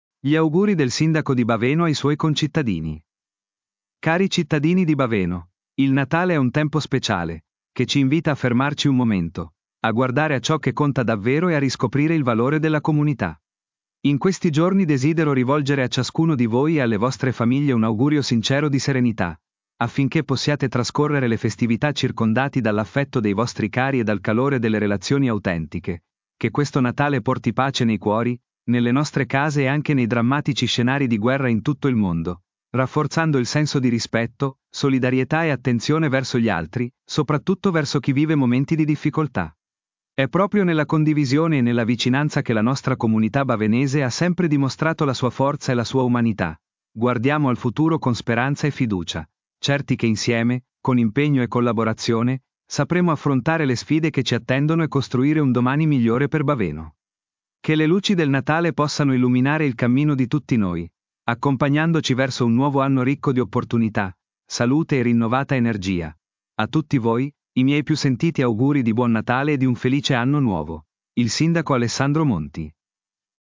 Gli auguri del sindaco di Baveno ai suoi concittadini